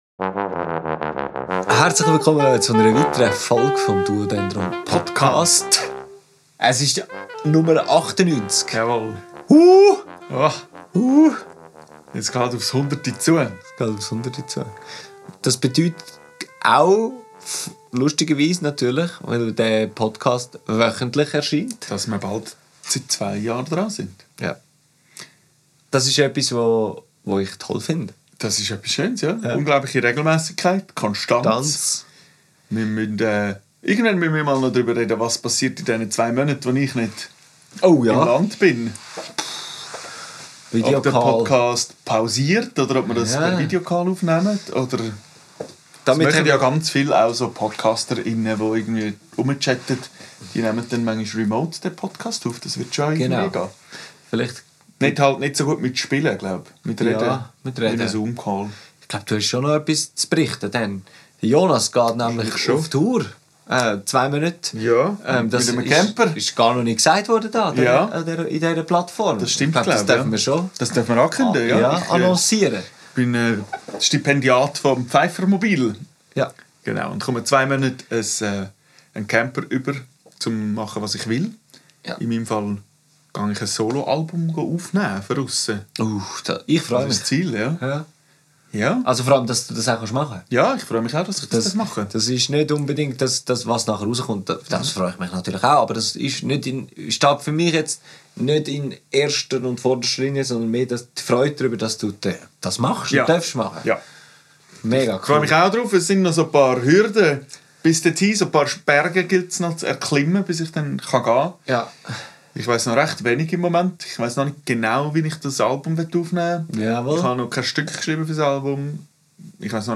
In dieser Folge wird wieder einmal so richtig palavert.